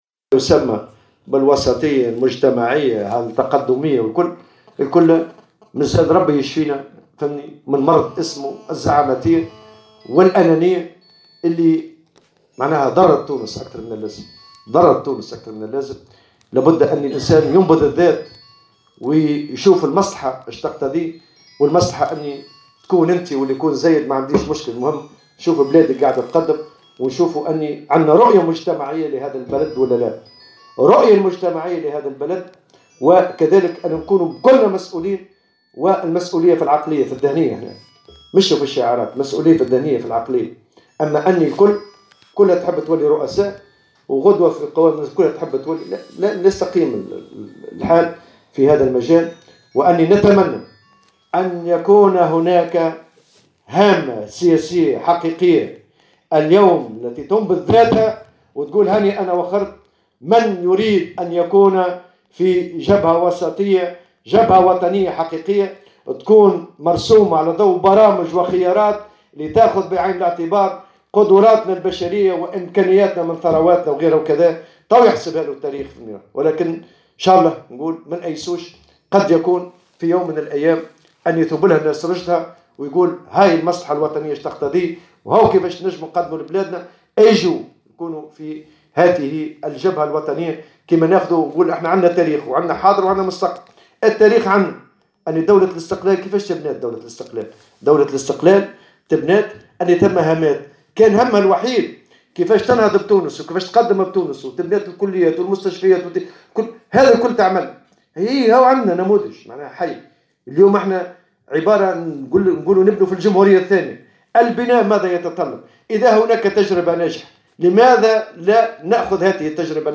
وقال الطبوبي في تصريح لمراسلة الجوهرة "اف ام" إن أغلبية الساسة اليوم مصابون بمرض الزعاماتية والأنانية وهي التي أضرت بمصلحة تونس أكثر من اللازم داعيا إلى ضرورة نبذ الذات وتغليب المصلحة الوطنية من خلال رؤية مجتمعية شاملة لتونس على حد قوله.